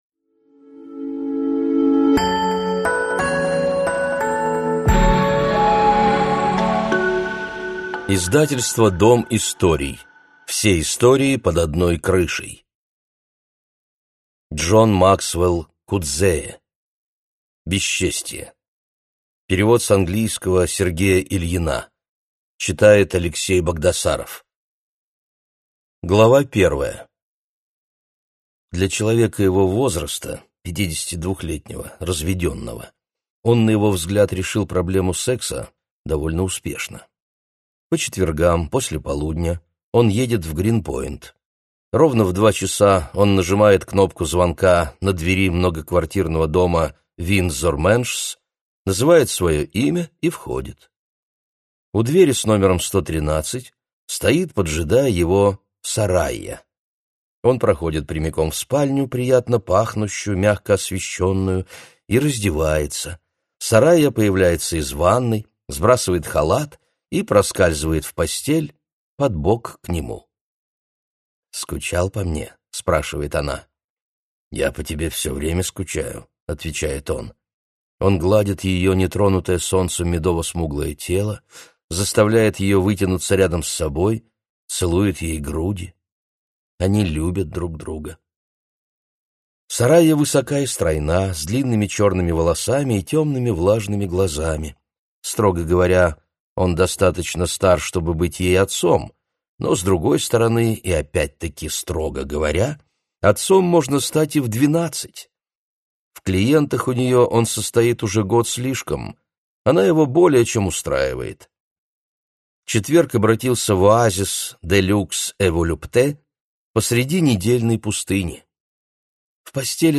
Аудиокнига Бесчестье | Библиотека аудиокниг